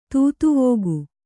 ♪ tūtuvōgu